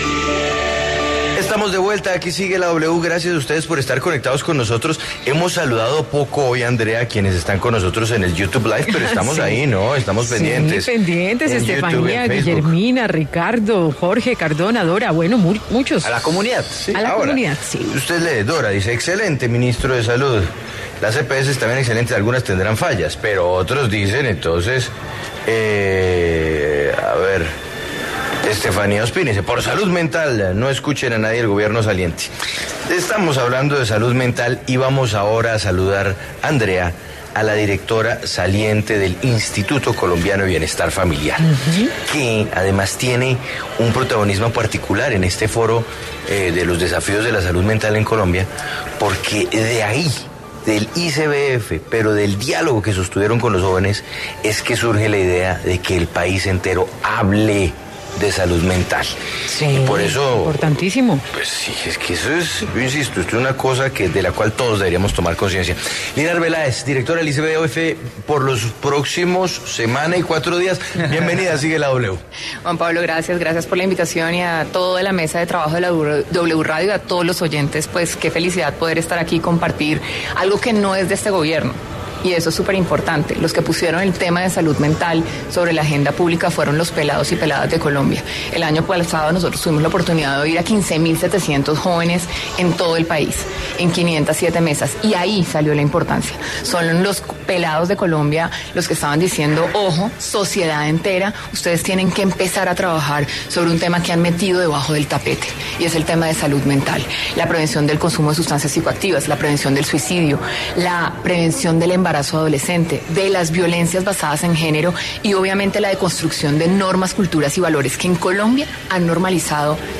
Lina Arbeláez, directora del Instituto Colombiano de Bienestar Familiar, ICBF, se refirió en Sigue La W sobre los principales retos para cuidar la salud mental de niños y adolescentes.
En el encabezado escuche las declaraciones de Lina Arbeláez, directora del Instituto Colombiano de Bienestar Familiar, ICBF, sobre la salud mental.